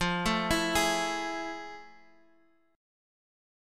Fmaj7#9 chord